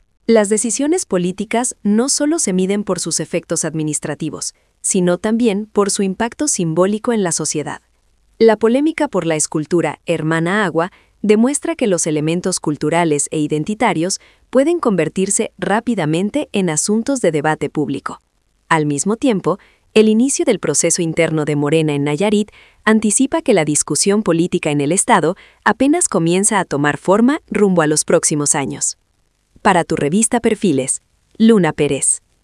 COMENTARIO EDITORIAL 🎙